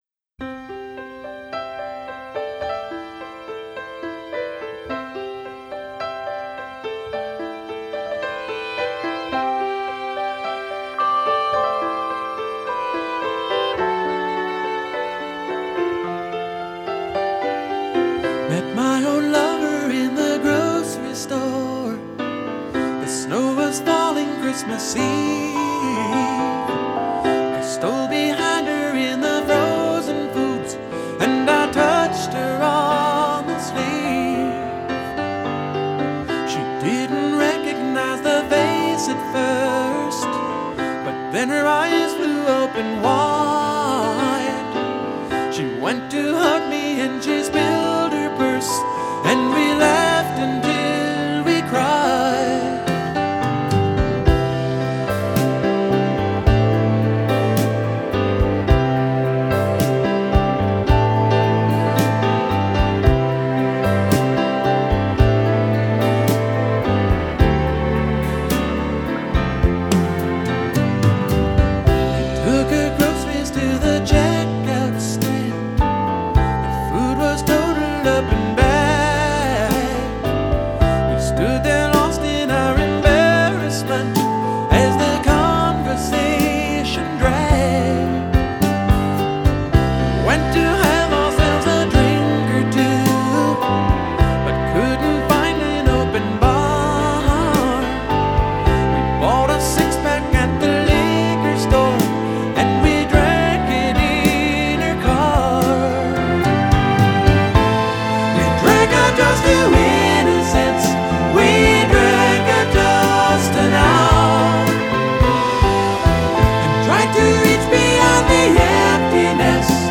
★ 美國最具代表性創作者之一，以輕柔歌聲、動人歌詞與吉他走紅70年代的民謠詩人！